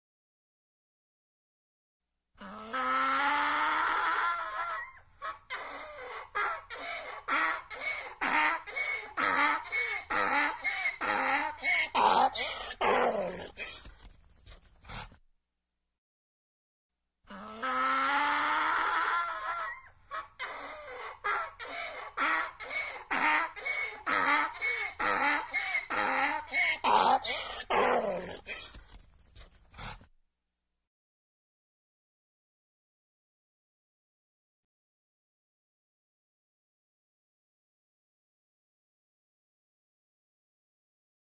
دانلود آهنگ خر از افکت صوتی انسان و موجودات زنده
جلوه های صوتی
دانلود صدای خر از ساعد نیوز با لینک مستقیم و کیفیت بالا